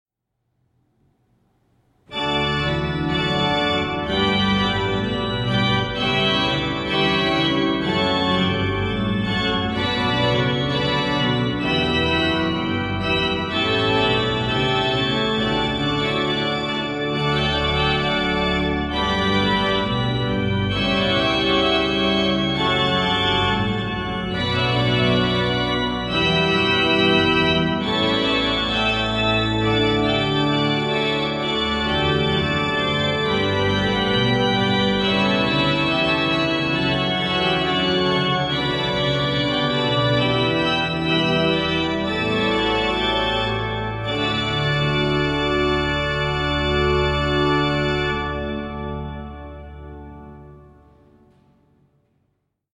D6 L’orgue a été construit en 1960 par la Maison Beuchet, de part et d’autre du chœur.
II/25 (22) - transmission électrique -